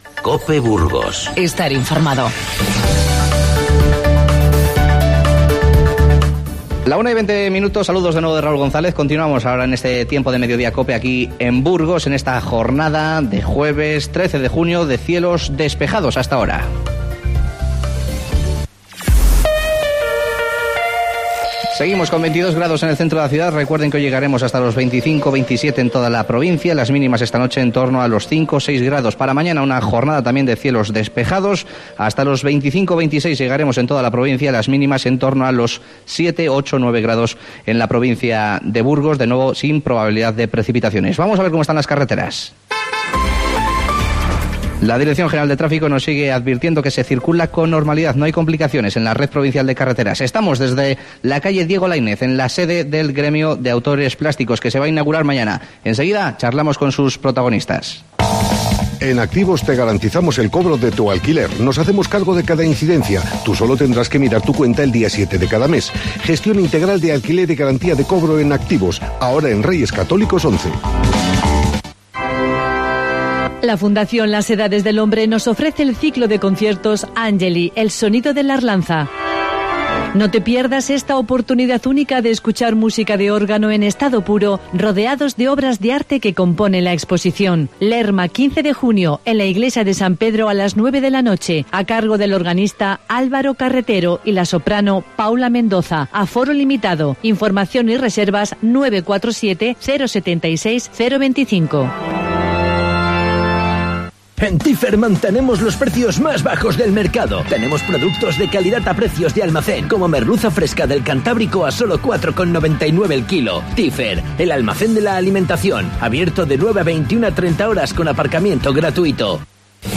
Charlamos con varios miembros del Gremio de Autores Plásticos, que inauguran mañana en la calle Diego Laínez de Burgos su nueva sede. También nos cuentan los próximos eventos que realizarán.